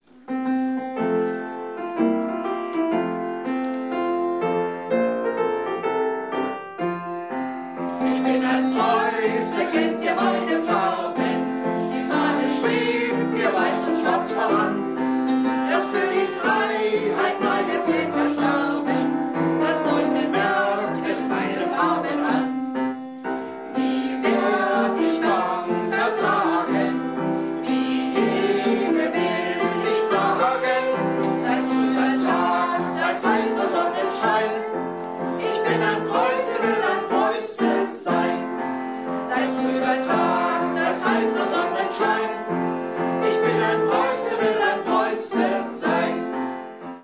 Eine Strophe mit Gesang
chor.mp3